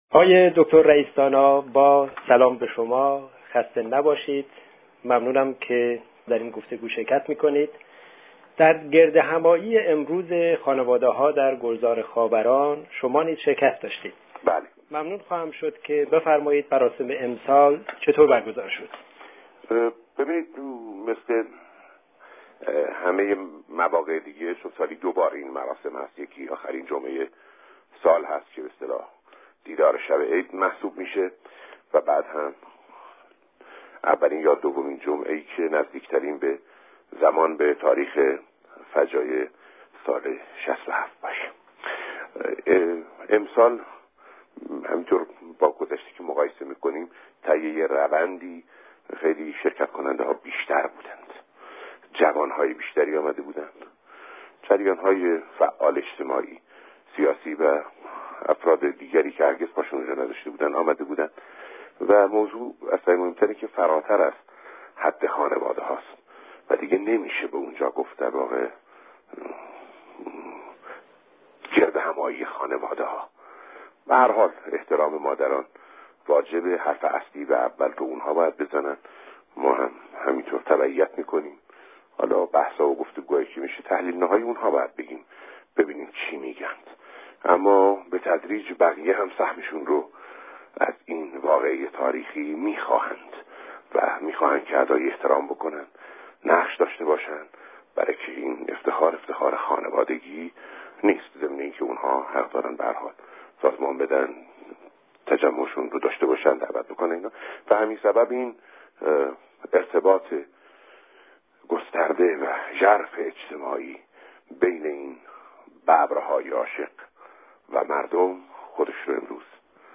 سخن بسیار دقیقی را فریبرز رئیس دانا، در پایان مراسم جمعه گذشته در گفتگو با رادیو برابری گفت که اگر عقلی در کار طراحان توطئه علیه خاوران باشد به گوش جان باید بشنوند.